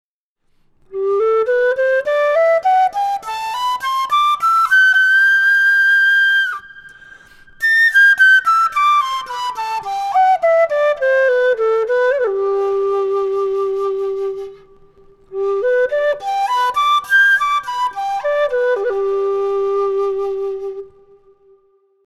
Кена (Quena, Jacaranda, Ramos, G) Перу
Длина (см): 38
Материал: жакаранда.